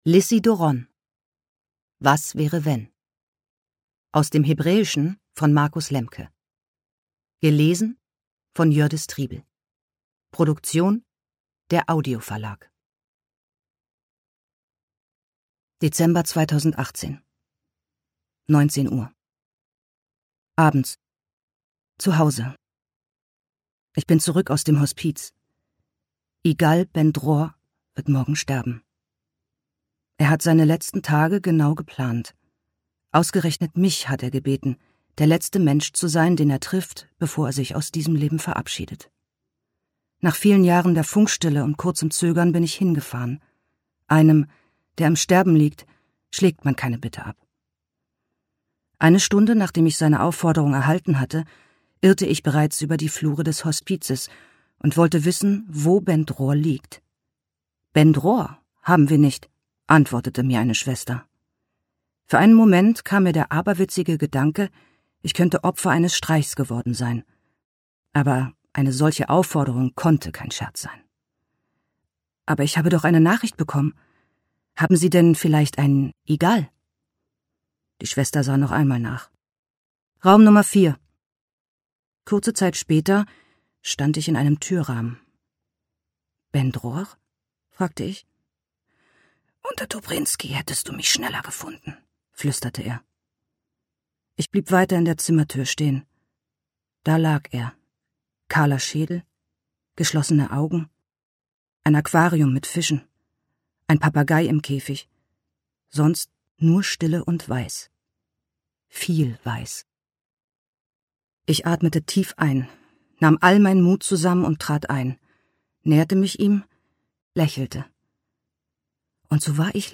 Was wäre wenn Lizzie Doron (Autor) Jördis Triebel (Sprecher) Audio-CD 2 CDs (2h 45min) 2021 | 1.